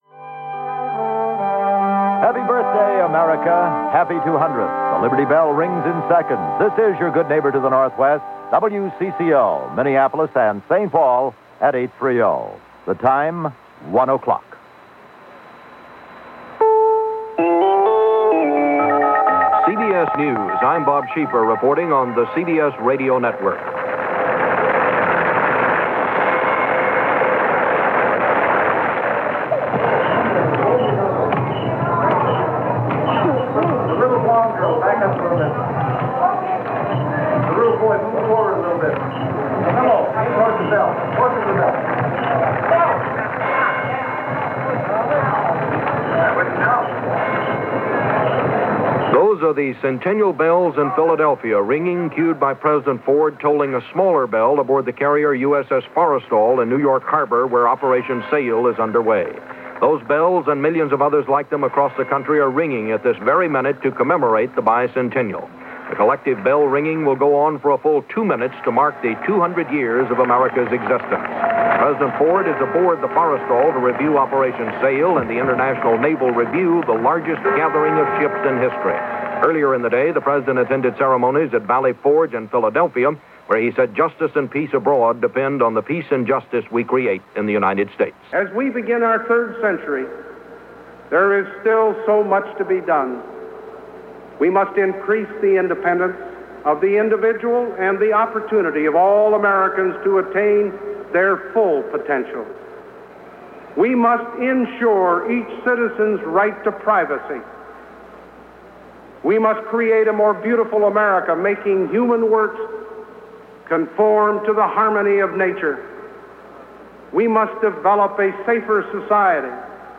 Bicentennial coverage – CBS Radio – July 4, 1976
So, as a reminder – here is a small (9 minute) sampling of how that day went, via CBS Radio as part of a look back on the year 1976.